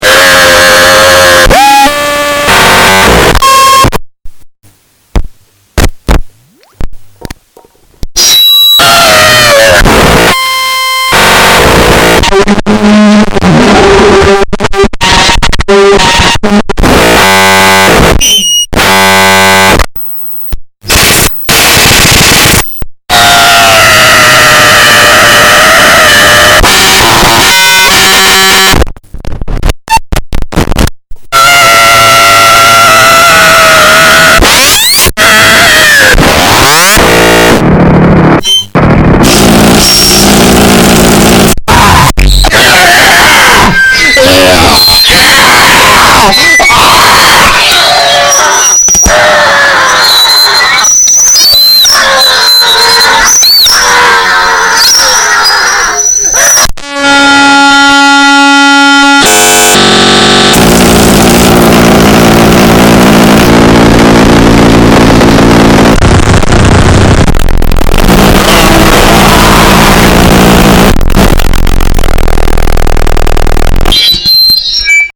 made with Soviet EQ and Chinese voicechanger